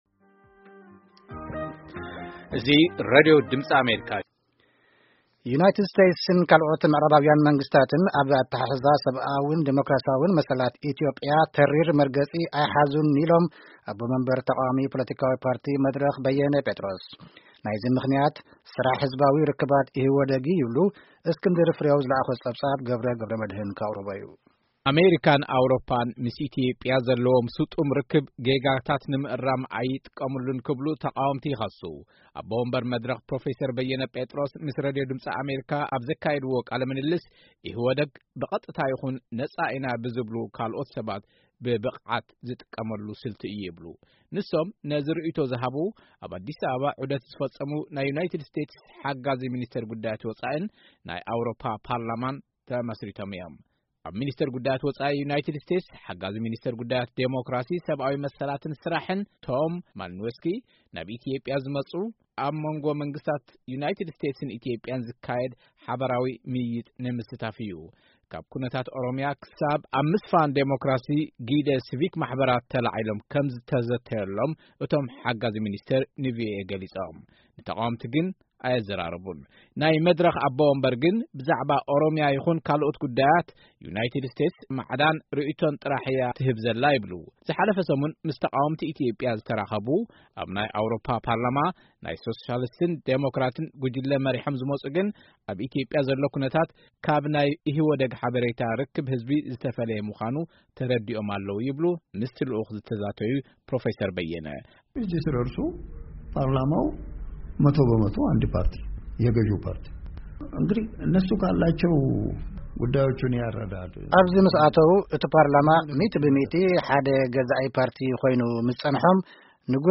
ኣሜሪካን ኣውሮጳን ምስ ኢትዮጵያ ዘለዎም ስጡም ርክብ ጌጋታት ንምእራም ኣይተጠቀሙሎምን ክብሉ ተቓወምቲ ይኸሱ።ኣቦ መንበር መድረኽ ፕሮፌሰር በየን ጴጥሮስ ምስ ሬድዮ ድምጺ ኣሜሪካ ኣብ ዘካየድዎ ቃለ ምልልስ ኢህወዴግ ብቐጥታ ይኹን ነጻ ኢና ብዝብሉ ካልኦት ሰባት ብብቕዓት ዝጥቀመሉ ስልቲ እዩ ይብሉ።